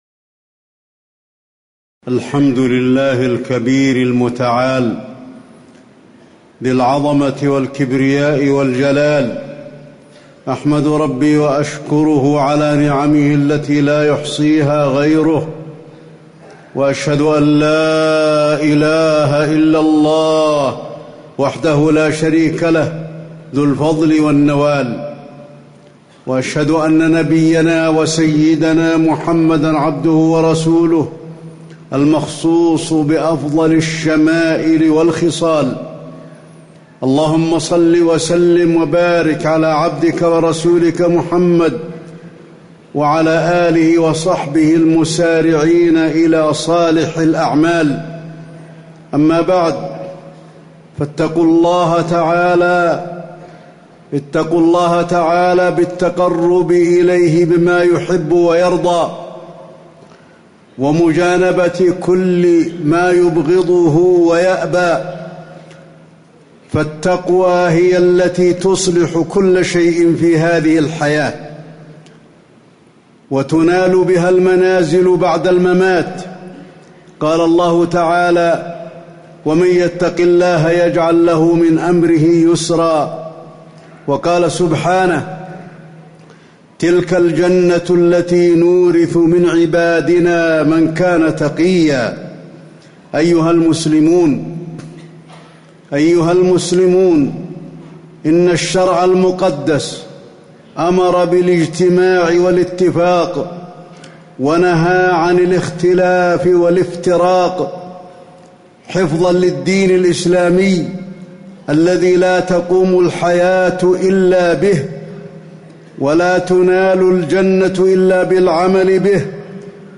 تاريخ النشر ٩ محرم ١٤٣٩ هـ المكان: المسجد النبوي الشيخ: فضيلة الشيخ د. علي بن عبدالرحمن الحذيفي فضيلة الشيخ د. علي بن عبدالرحمن الحذيفي التحذير من التفرق والإختلاف والطمع في الدنيا